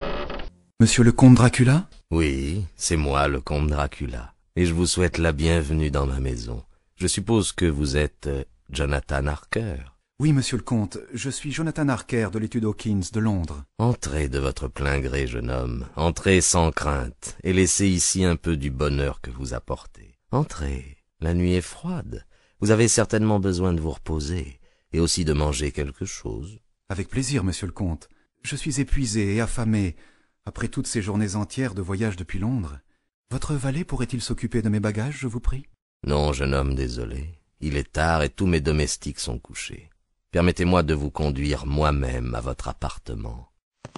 Musiques : Nicolaï Rimsky-Korsakov